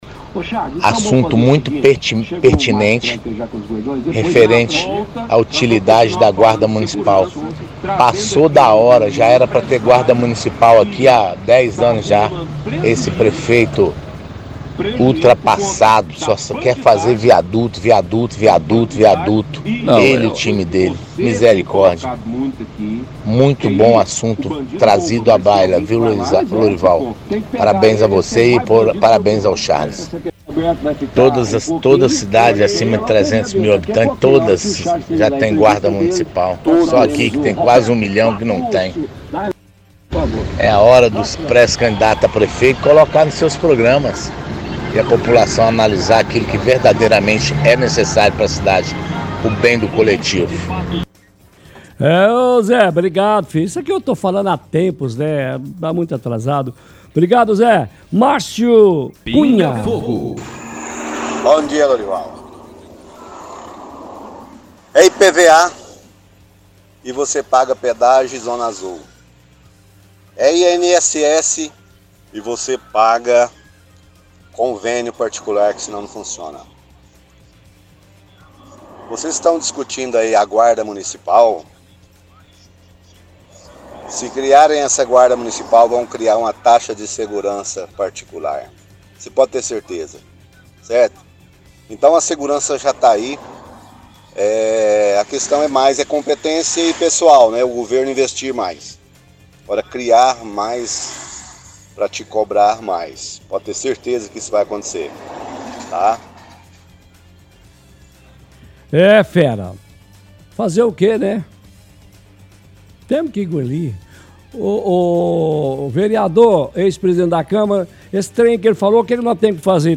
– Ouvinte fala que seria muito bom a guarda municipal na cidade para melhoria da cidade
– Ouvinte questiona qual a diferença de guarda municipal e policial aqui na cidade